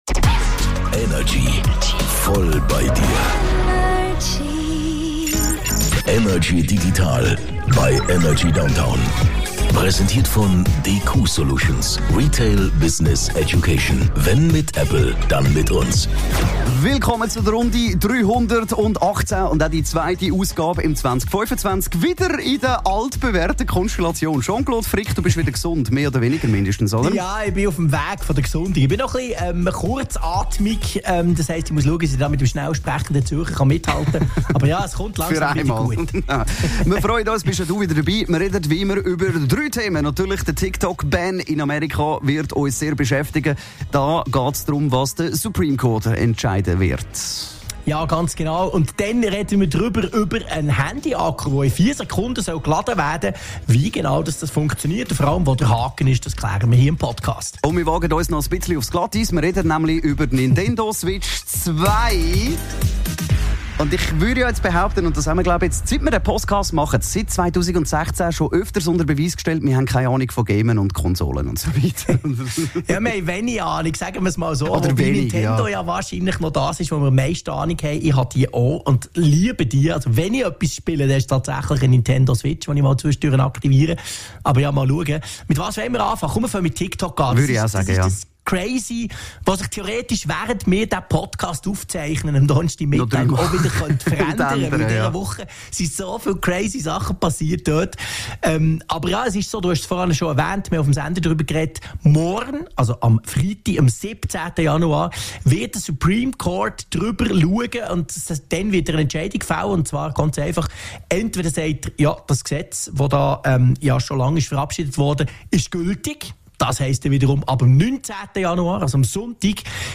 im Energy Studio
aus dem HomeOffice über die digitalen Themen der Woche.